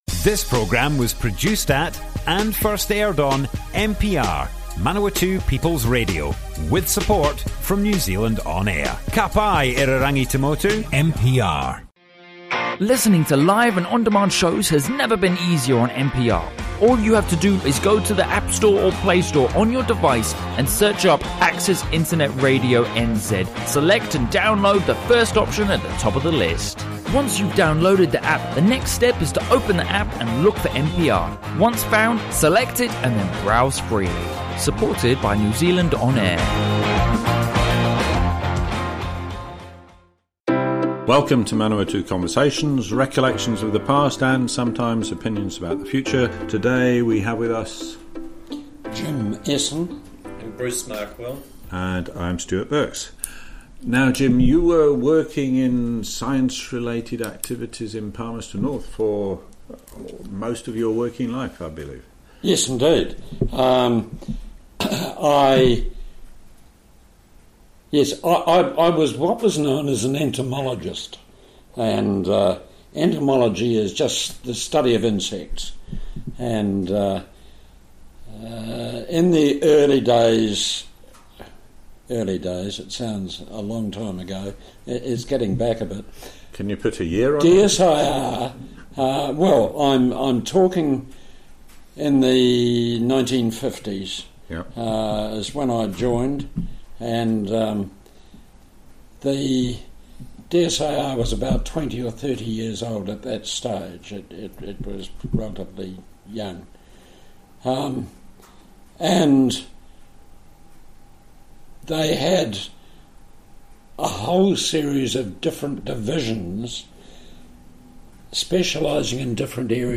Broadcast on Manawatu People's Radio 16 July, 2019.